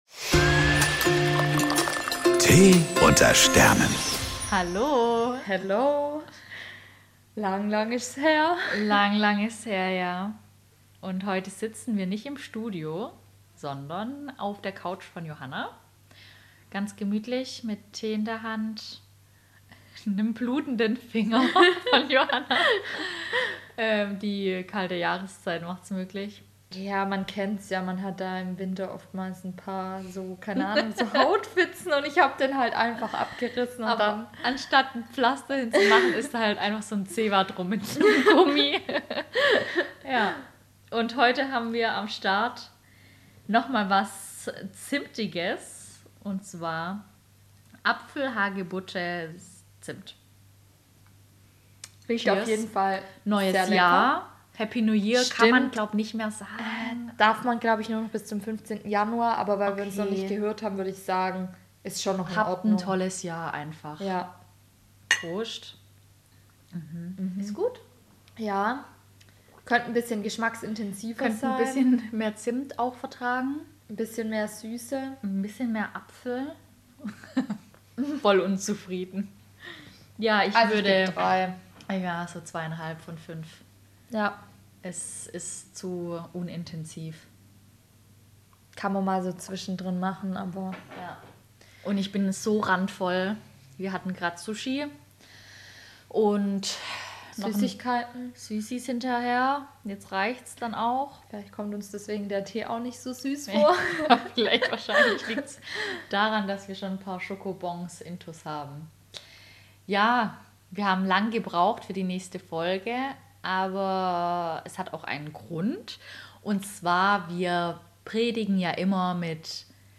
Was die Sterne zu 2025 sagen und was unser persönliches Gefühl ist, hört ihr hier! PS: Sorry für die Qualität dieses Mal!